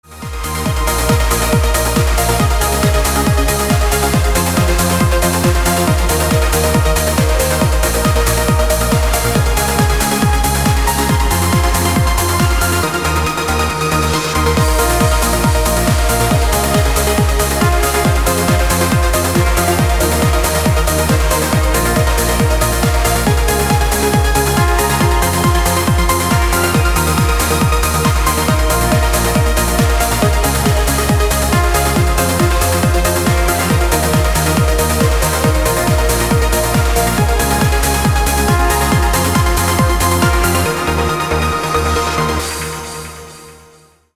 • Качество: 320, Stereo
громкие
электронная музыка
без слов
Trance
быстрые
динамичные
Uplifting trance